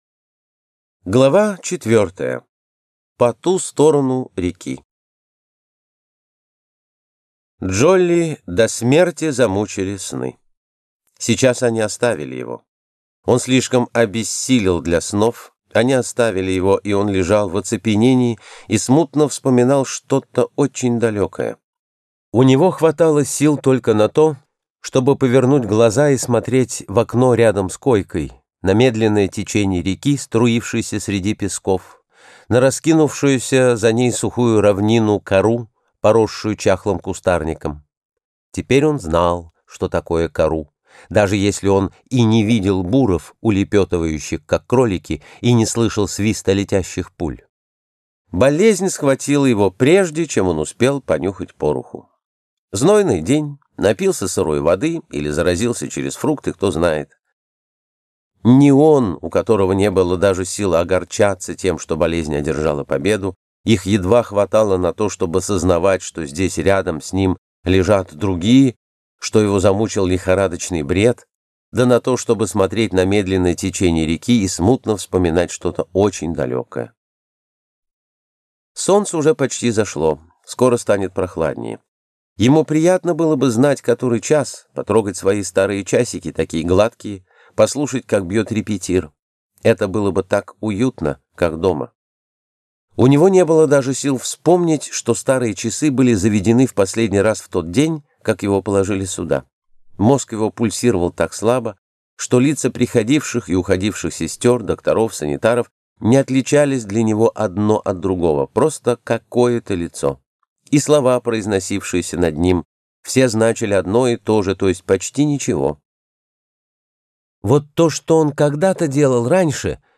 Аудиокнига Сага о Форсайтах: В петле | Библиотека аудиокниг